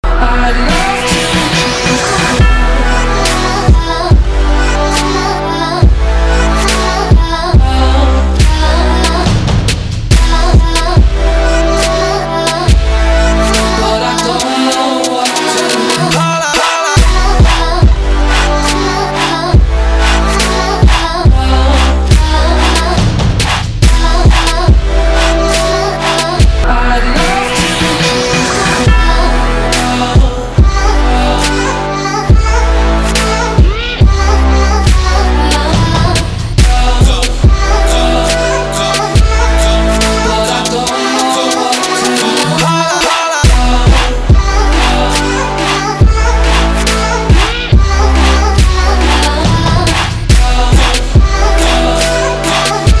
• Качество: 256, Stereo
классный клубнячок